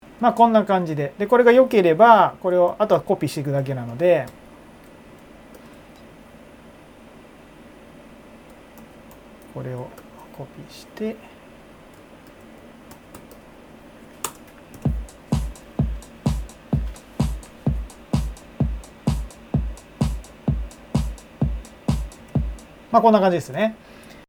先日YouTube用の動画撮影時に、うっかりエアコンを入れっぱなしにしてしまった音声データです。
冒頭5秒程喋ってます。
また、5秒程経ったところで無音になりますが、ビフォーではうっすらエアコンだけでなくキーボードを叩く音などが入ってます。
さらに、サンプル曲を再生させた音がスピーカーでそこそこでかい音だったためマイクで拾ってしまっていますが、それもごっそり消すことができています。
rx7-noise-test-before.mp3